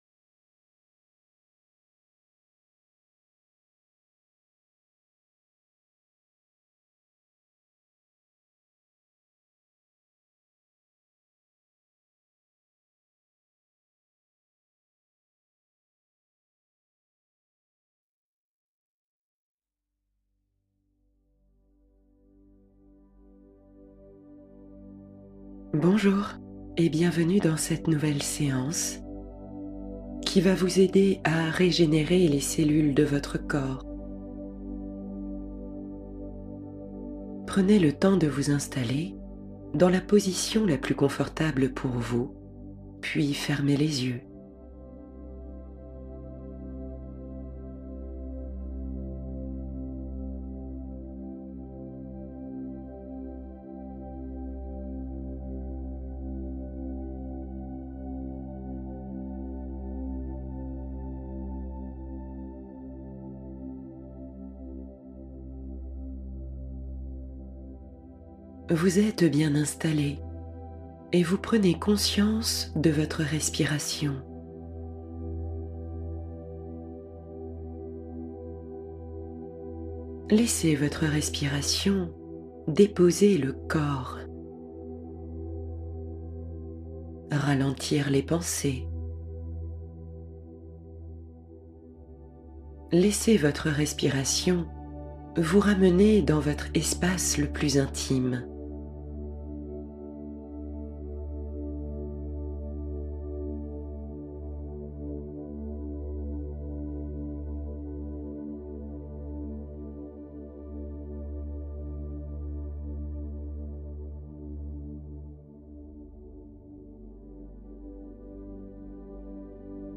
Relaxation Guidée